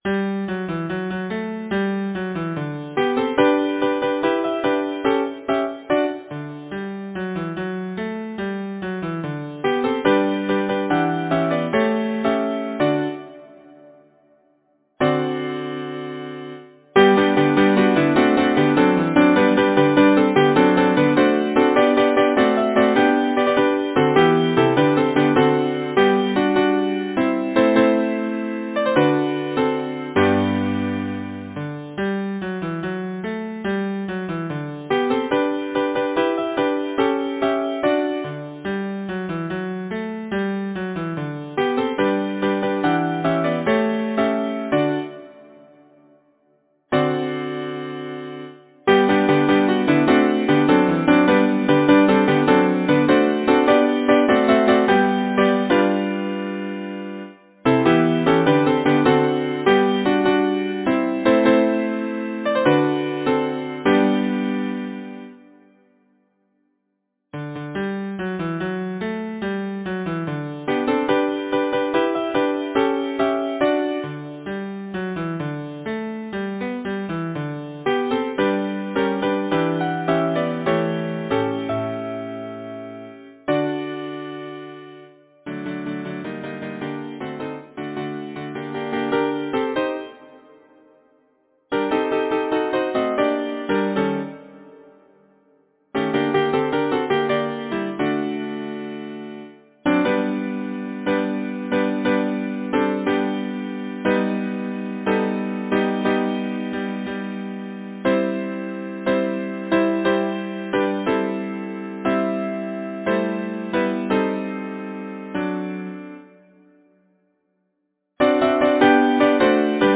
Title: Where are you going my pretty maid? Composer: Alfred James Caldicott Lyricist: Number of voices: 4vv Voicing: SATB Genre: Secular, Partsong, Nursery rhyme, Humorous song
Language: English Instruments: A cappella